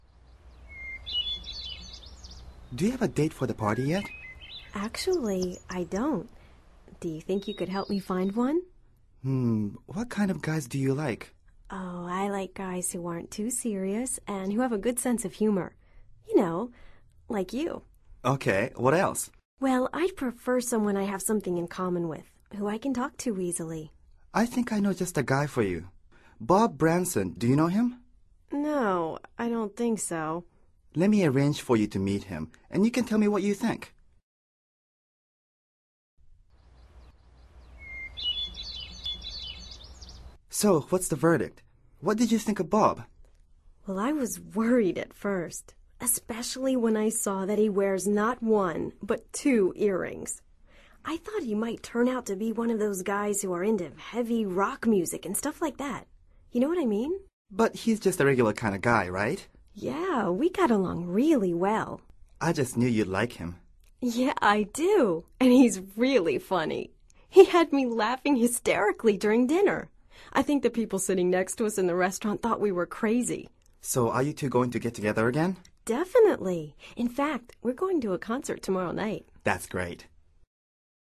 Escucha el audio y concéntrate en la entonación y ritmo de las frases.